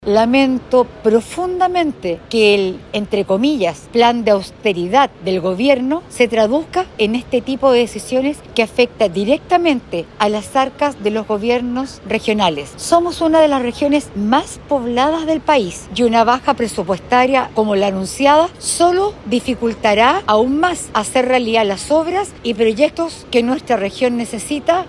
Por su parte, Marcela Varas, CORE del Partido por la Democracia de la provincia de Valparaíso, aseguró que este plan del Gobierno central dificultaría llevar a cabo las obras y proyectos que la región necesita.